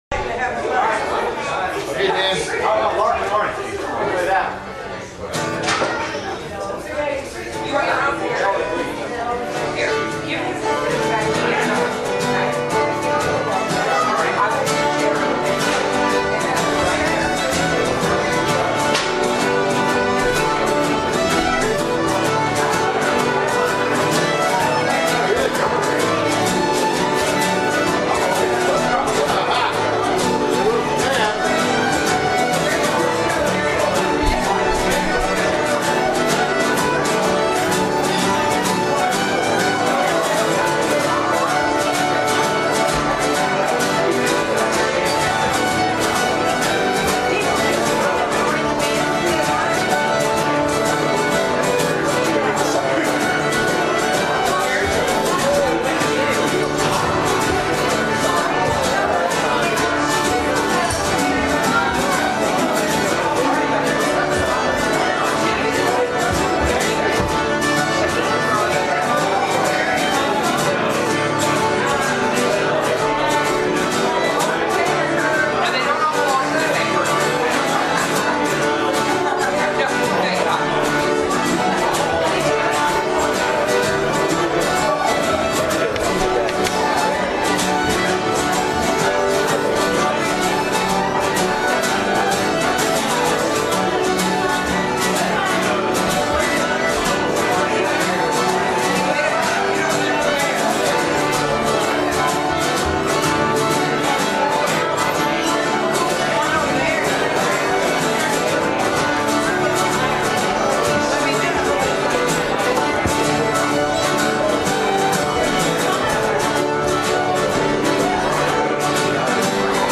Initially there were seven: two guitars, one accordion, two violins, a mandolin, and a slight woman with a small harp. Luckily, I had my recorder with me, so I sampled their set.  I chose not to edit background noise/conversation, as it was part of the experience.  Listen to the first two songs below.
live-irish-music-at-the-globe-3.mp3